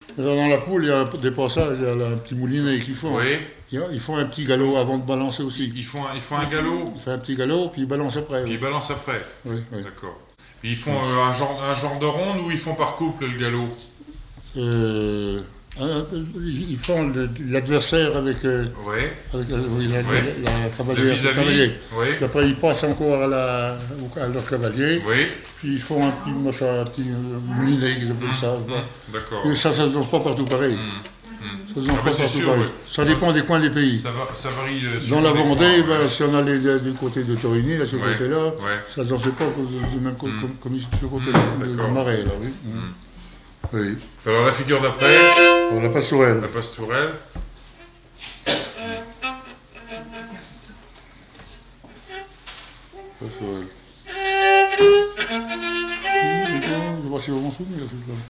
Enquête Les Bottarouzous, de Triaize (Association culturelle)
Catégorie Témoignage